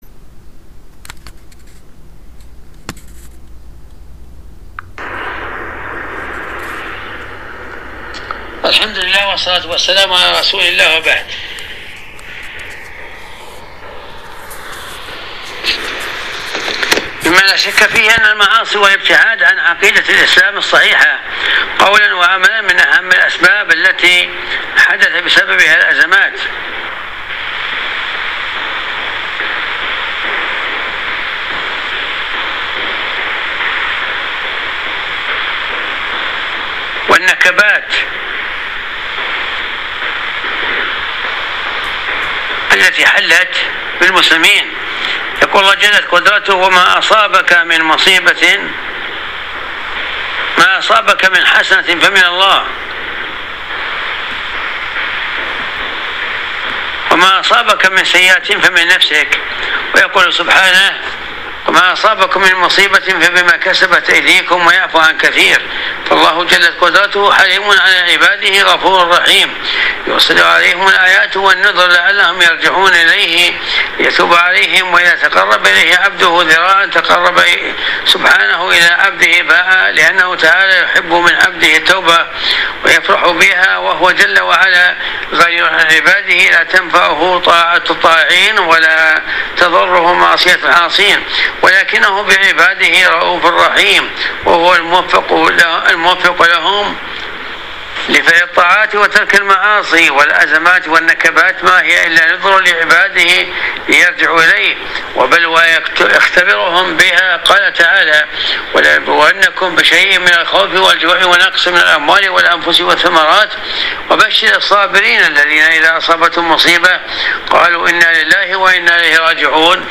الدرس 188 ج 5 أسباب الأزمات